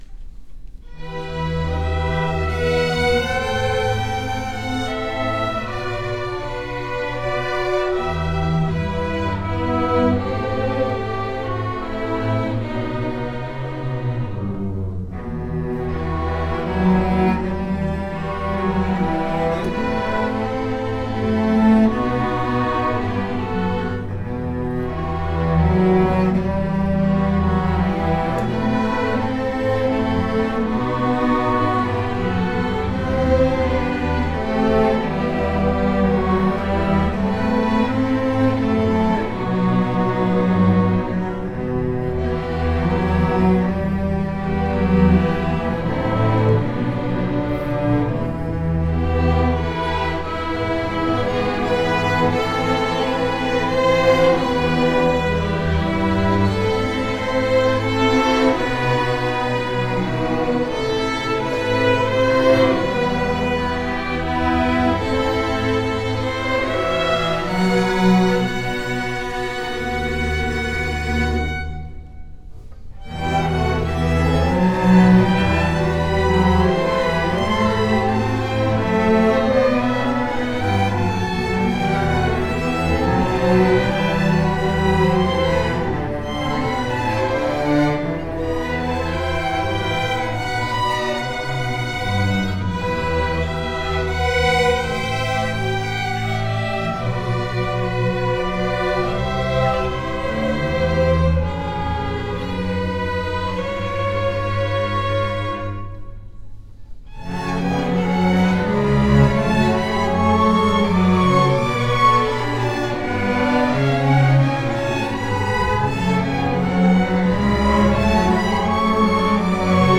Orchestra District MPA 2020 – March 10 – 12 at Largo High School